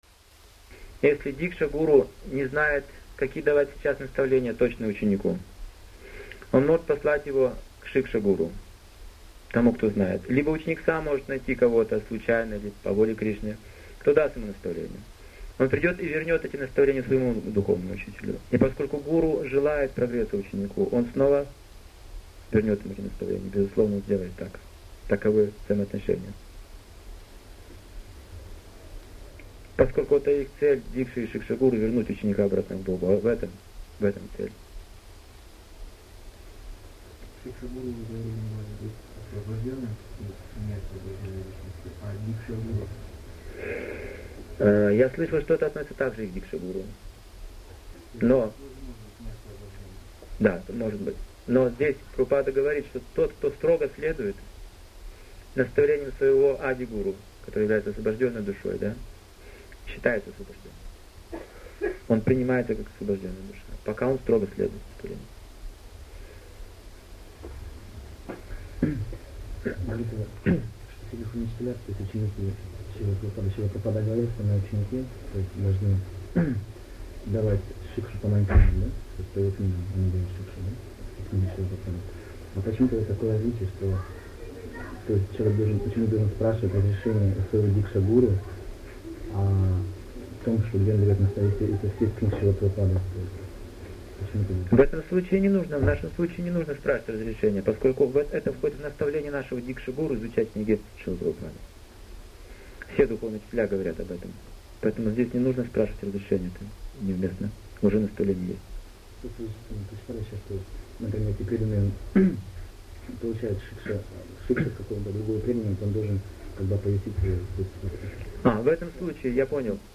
Лекция 1